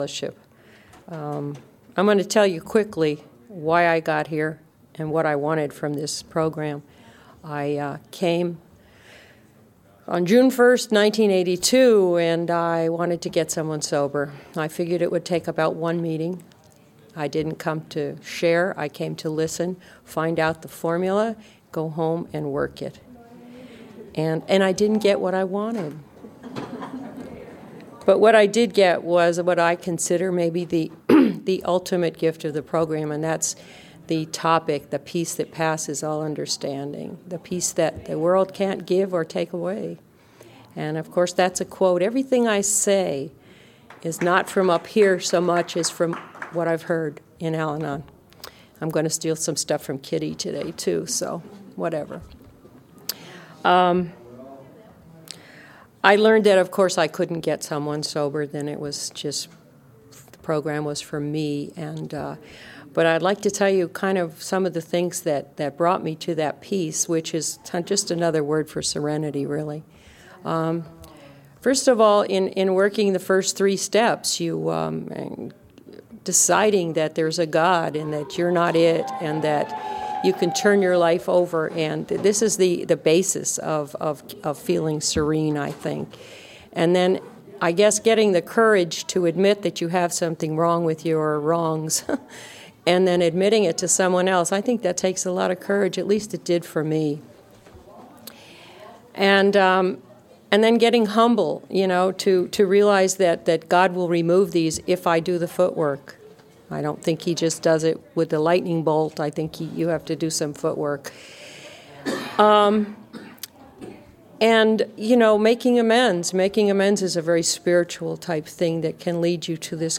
Saturday Al-Anon Speaker